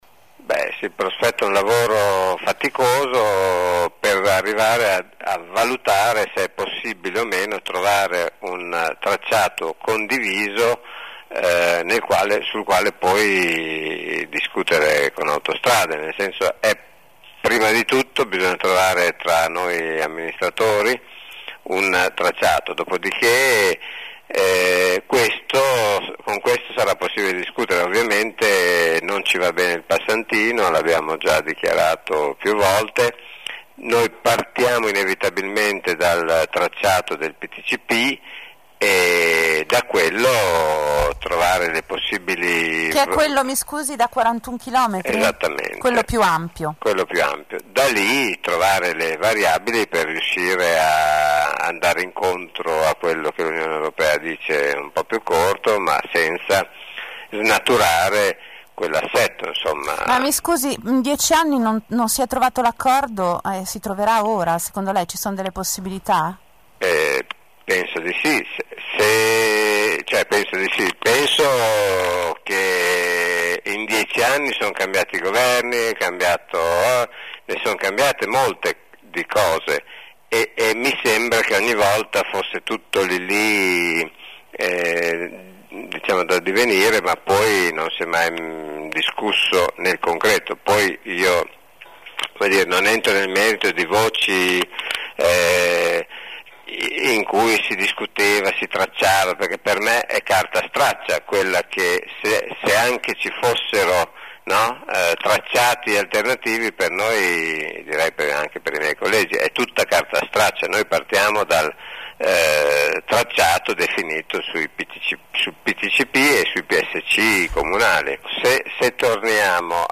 Come ci spiega Marco Monesi, sindaco di Castel Maggiore, per cui comunque il Passante va fatto: